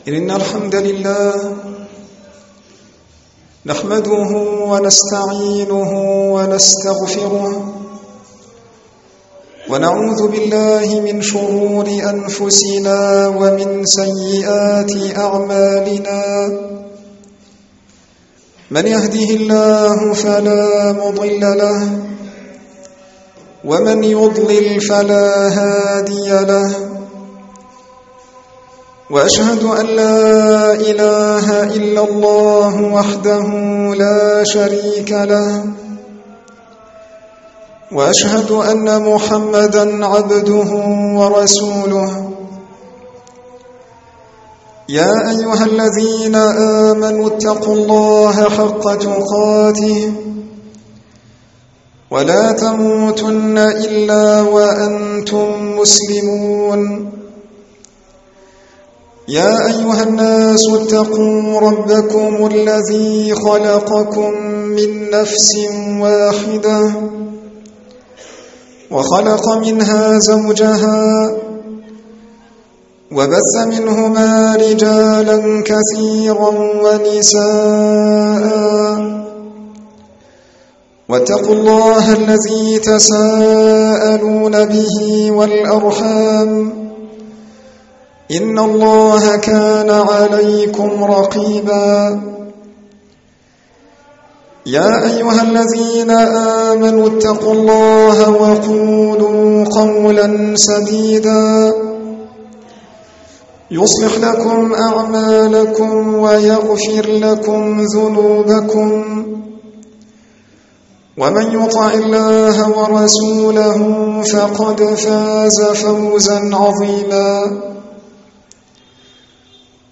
خطب عامة